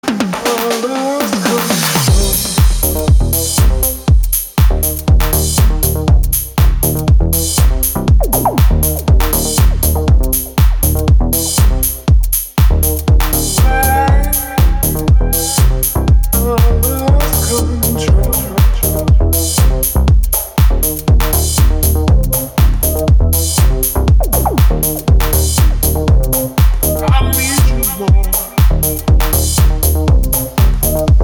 • Качество: 320, Stereo
мужской голос
громкие
deep house
dance
Electronic
EDM
Tech House